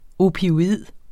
Udtale [ opioˈiðˀ ]